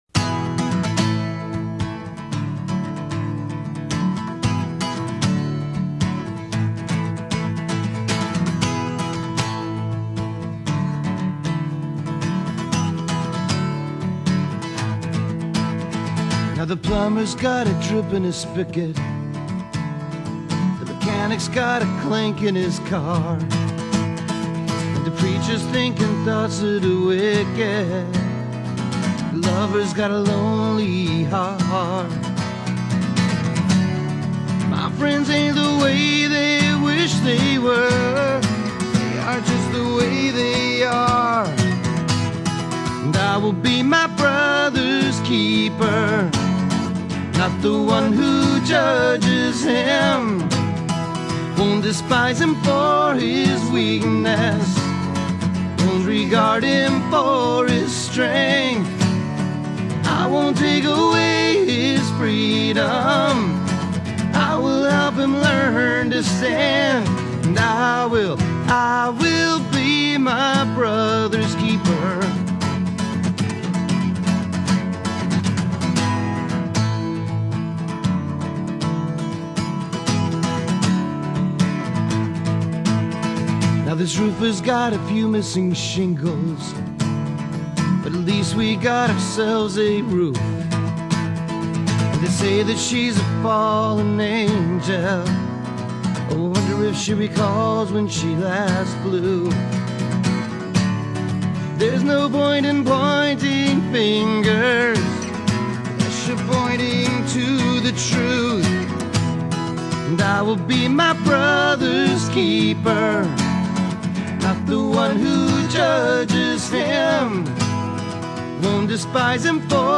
3. Sunday Worship – Second Song: